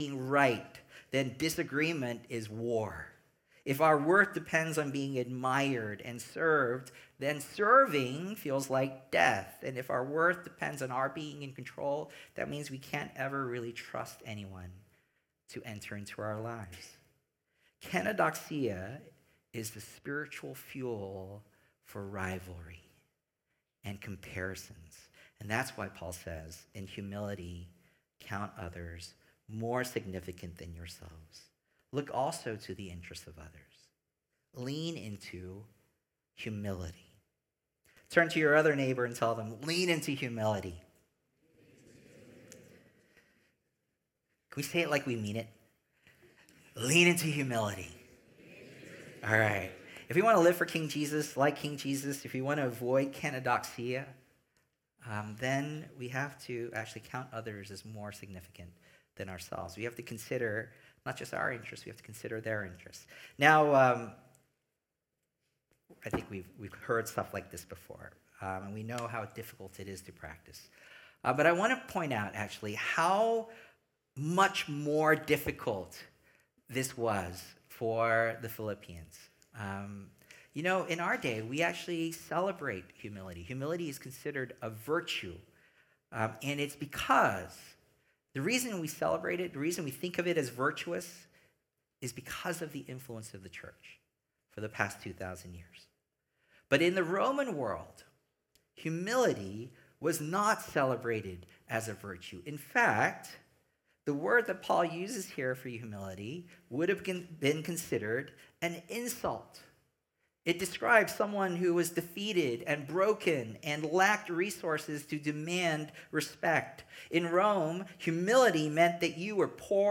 Sermons | Symphony Church